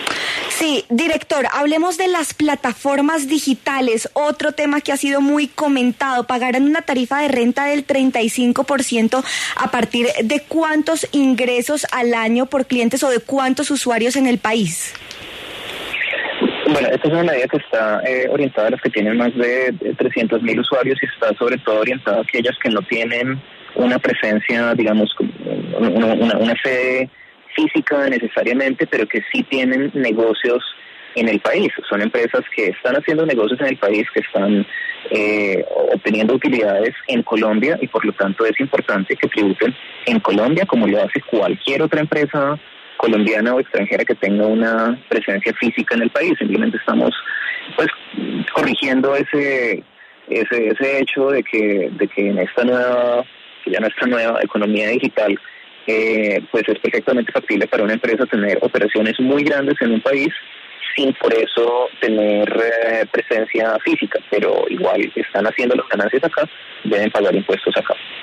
Escuche la entrevista completa al director de la DIAN: